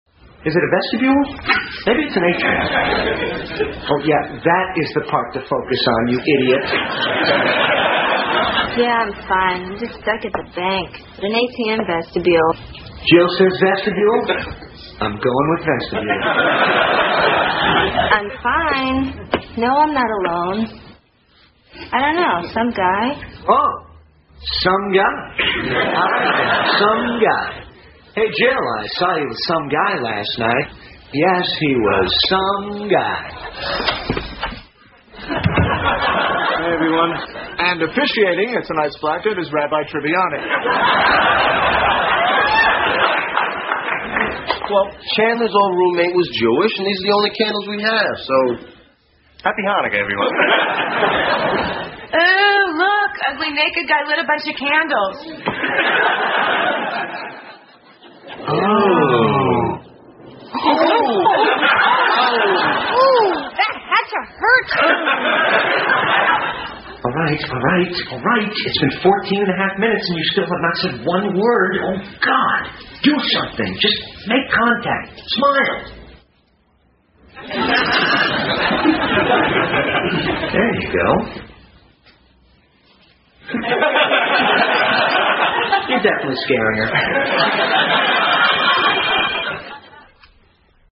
在线英语听力室老友记精校版第1季 第75期:停电(2)的听力文件下载, 《老友记精校版》是美国乃至全世界最受欢迎的情景喜剧，一共拍摄了10季，以其幽默的对白和与现实生活的贴近吸引了无数的观众，精校版栏目搭配高音质音频与同步双语字幕，是练习提升英语听力水平，积累英语知识的好帮手。